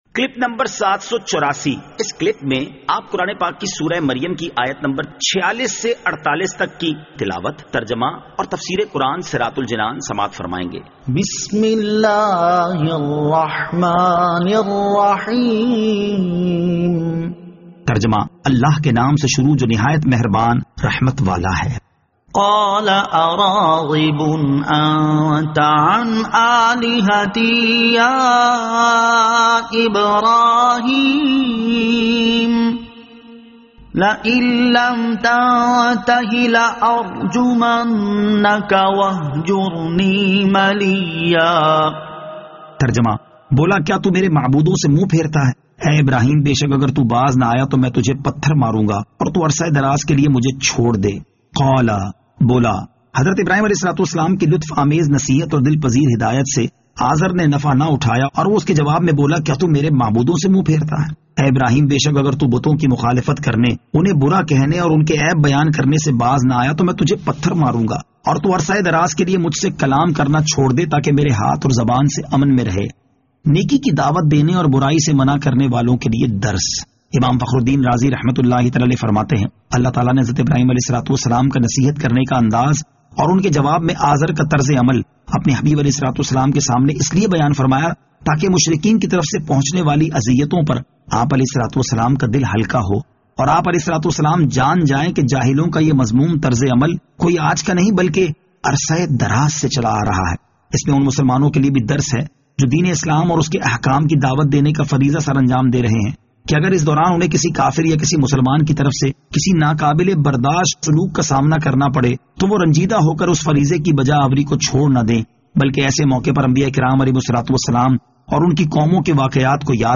Surah Maryam Ayat 46 To 48 Tilawat , Tarjama , Tafseer